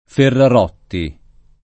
[ ferrar 0 tti ]